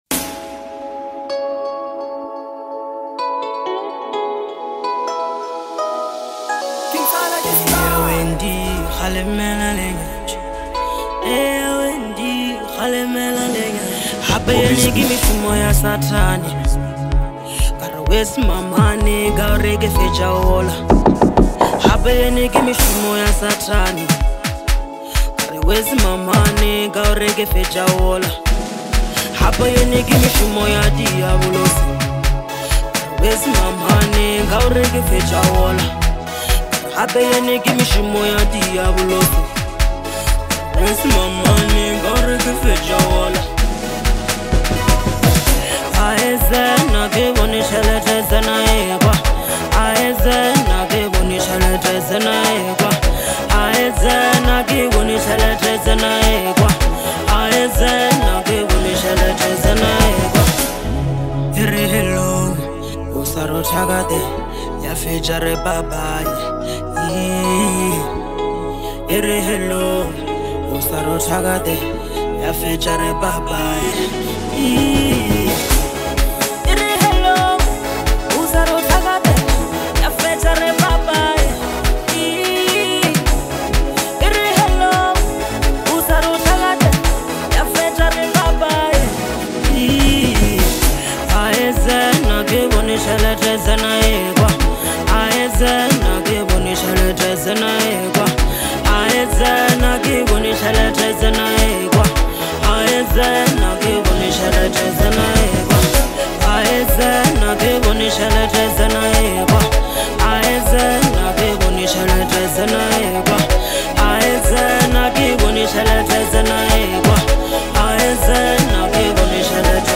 commanding vocals